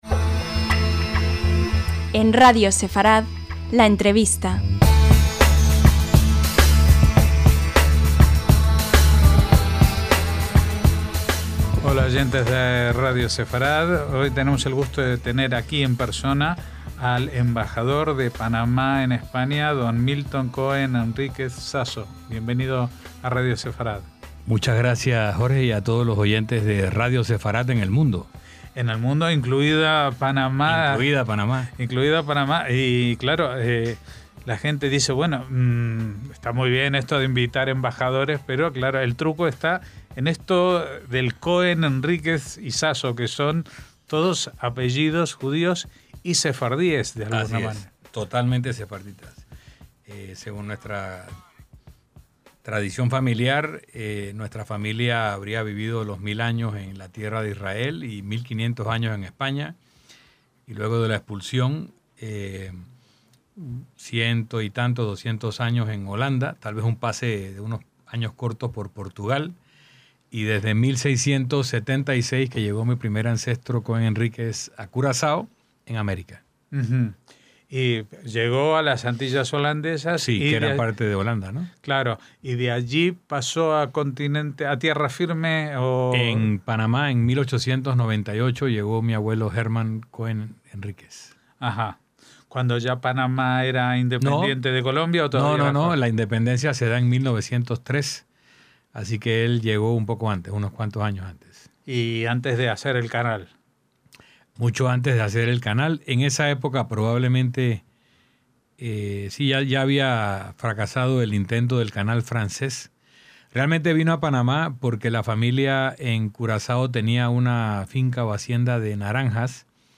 LA ENTREVISTA - Hablar con el Embajador de Panamá en España, Milton Cohen-Henríquez Sasso, es verse arrastrado por una vorágine de conocimiento, pasión y carisma a los que cuesta mucho poner cierre.